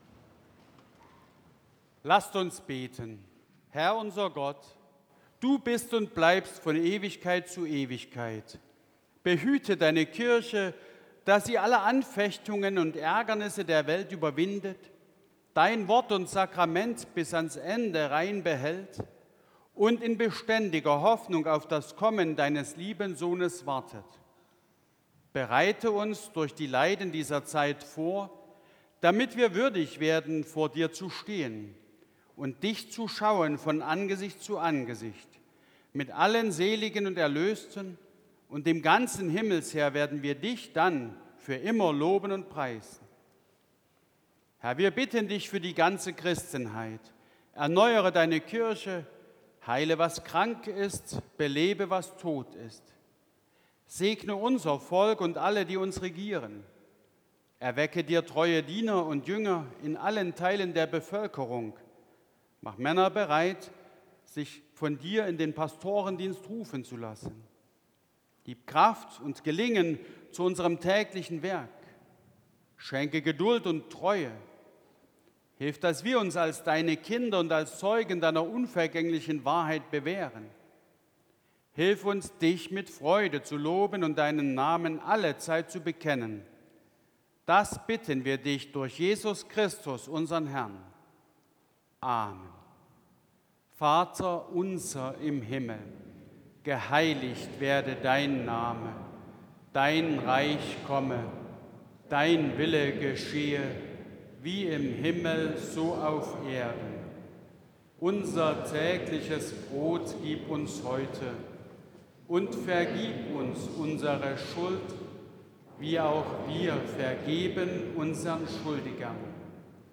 Audiomitschnitt unseres Gottesdienstes vom Vorletzten Sonntag im Kirchenjahr 2024